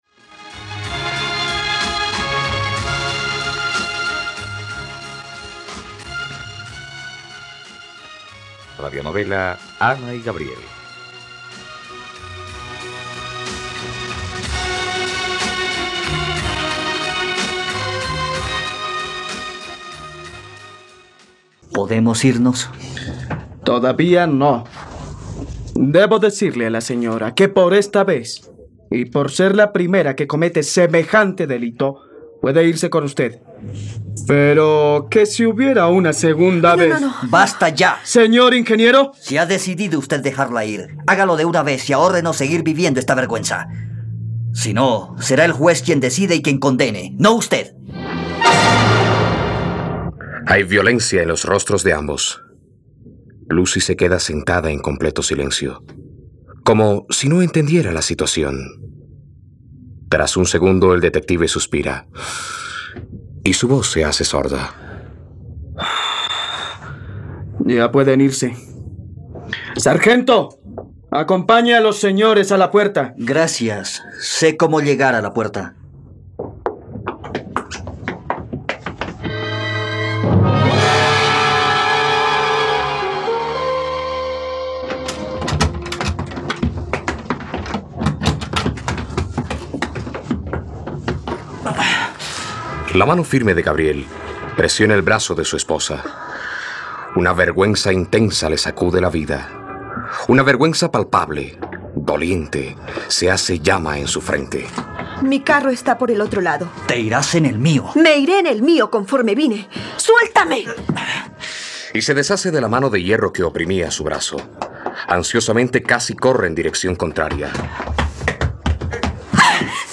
Ana y Gabriel - Radionovela, capítulo 16 | RTVCPlay